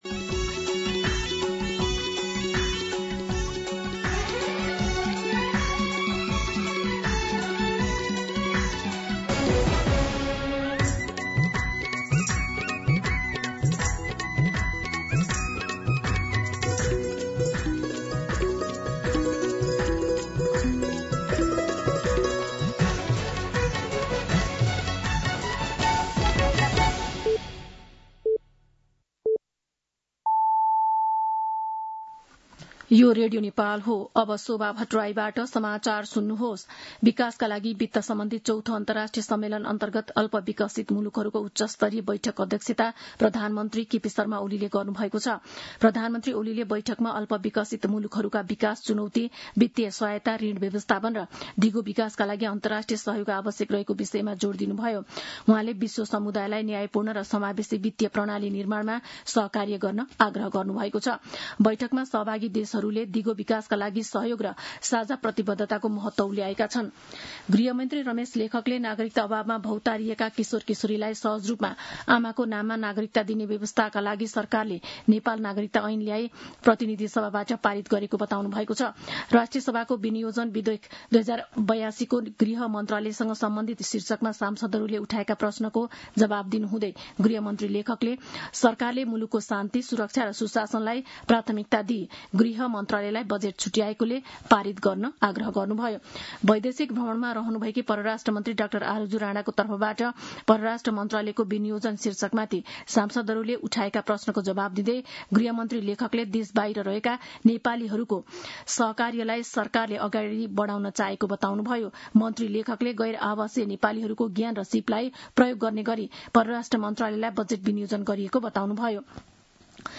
दिउँसो ४ बजेको नेपाली समाचार : १७ असार , २०८२